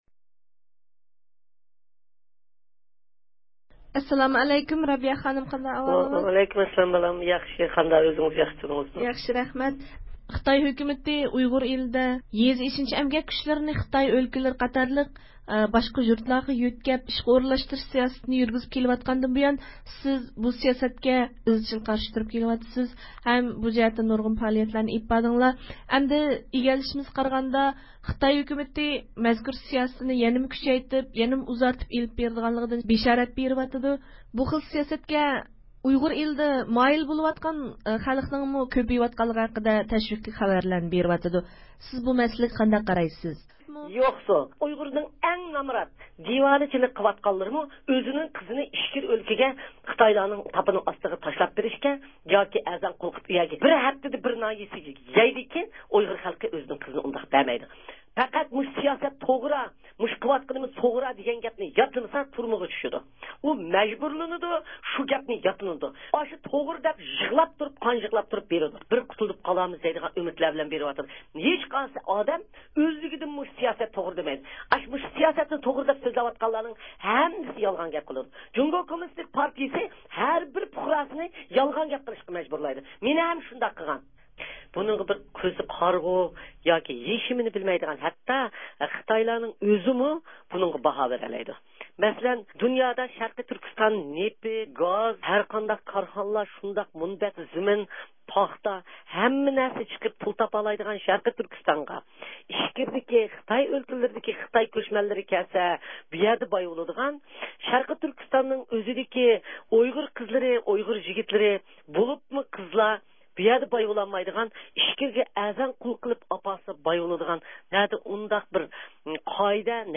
سۆھبىتىنىڭ